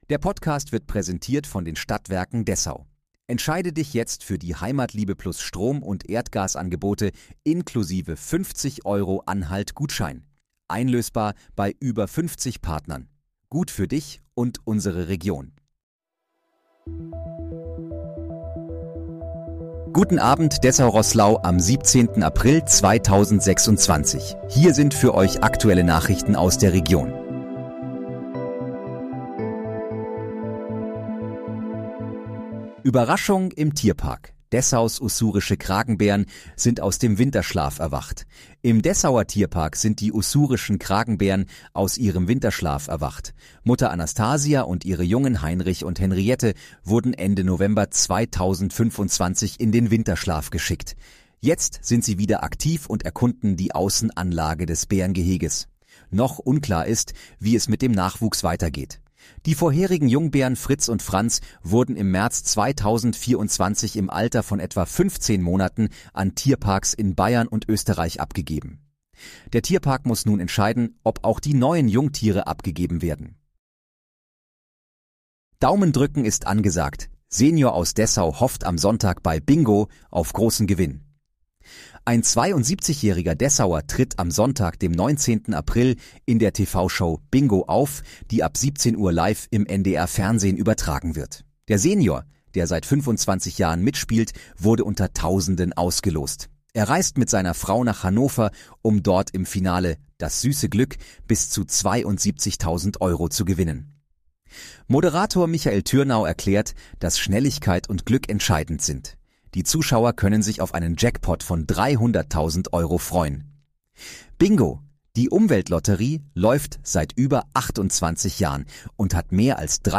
Guten Abend, Dessau-Roßlau: Aktuelle Nachrichten vom 17.04.2026, erstellt mit KI-Unterstützung